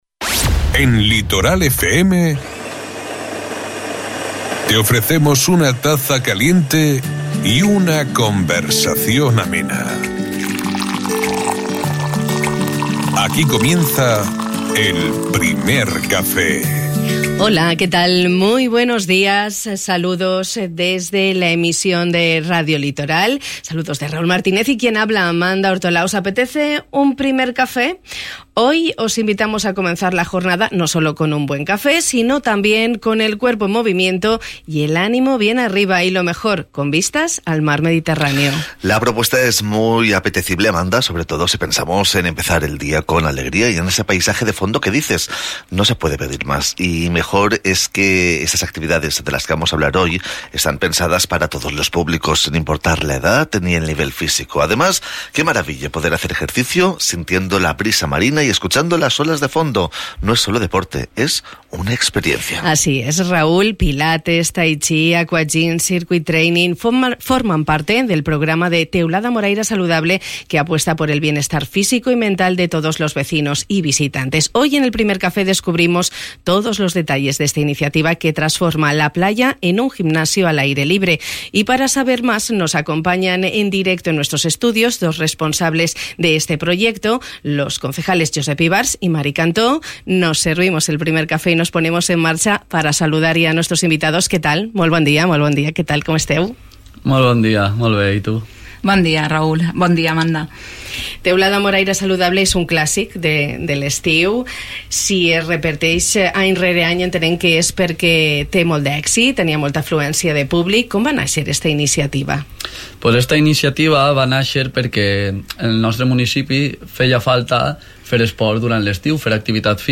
Cosa que és possible amb el programa Teulada Moraira Saludable, com ens han explicat els regidors de l'ajuntament teuladino Josep Ivars i Mari Cantó.